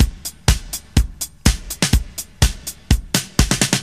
• 124 Bpm Drum Beat A# Key.wav
Free breakbeat sample - kick tuned to the A# note. Loudest frequency: 2559Hz
124-bpm-drum-beat-a-sharp-key-Ahf.wav